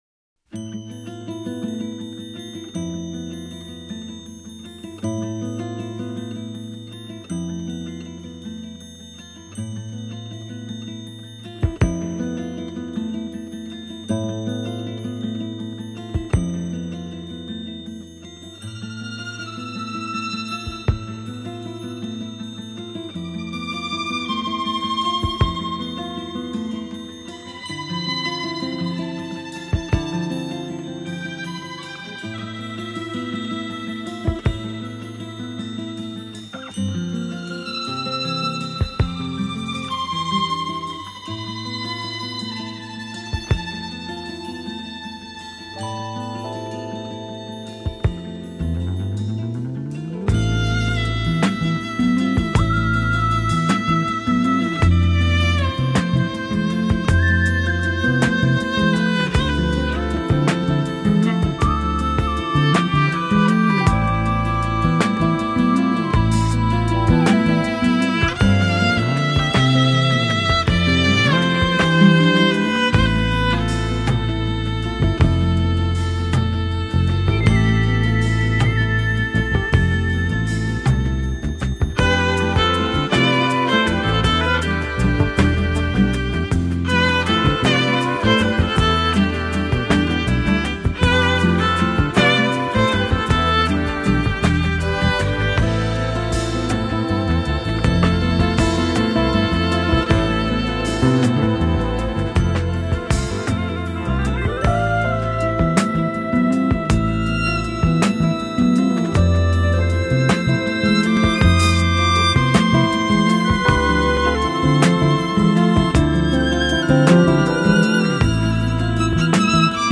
Jazz, Smooth Jazz, Jazz Violin